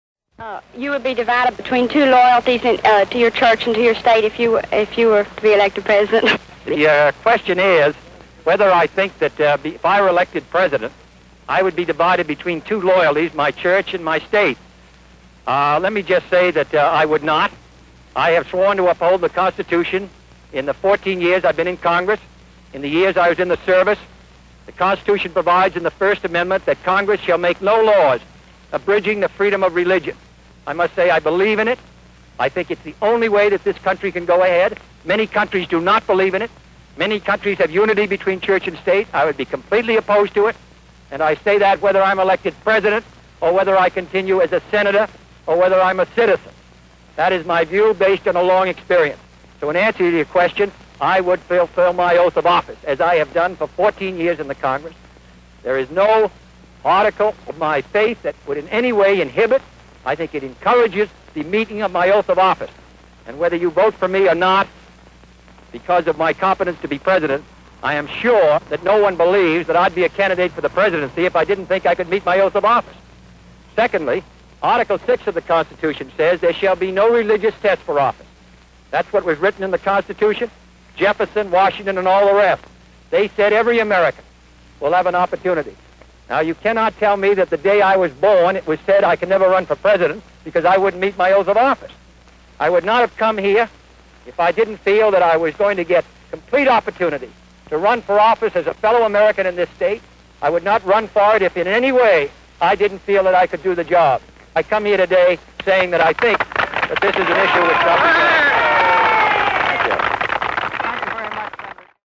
Dans un enregistrement conservé par le Museum of the Moving Image, il répond à une question sur son affiliation religieuse. Son interlocutrice lui demande s’il ne serait pas partagé entre deux loyautés en cas d’élection à la présidence, celle qu’il doit à son Église, celle qu’il doit à son État. Sa réponse s’appuie sur l’article 6 de la Constitution et sur le premier amendement.